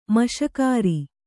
♪ maśakāri